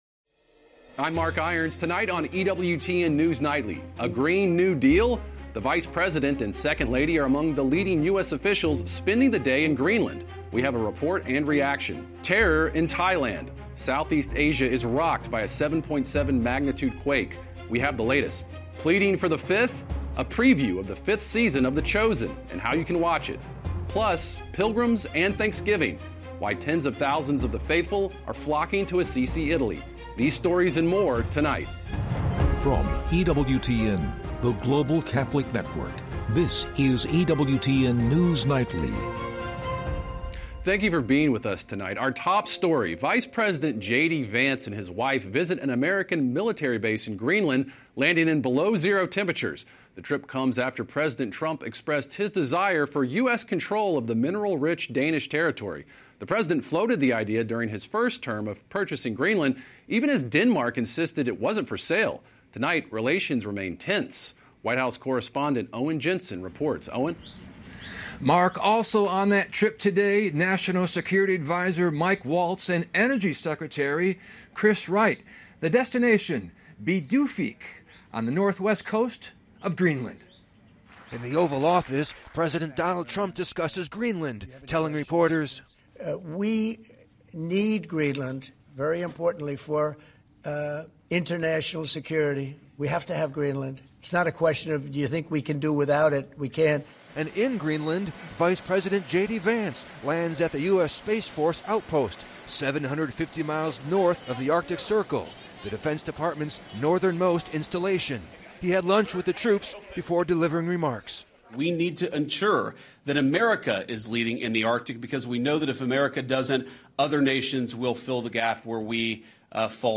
EWTN News Nightly is our daily news and analysis program presenting breaking Catholic News worldwide, top stories, and daily reports from the White House, Capitol Hill, and Rome.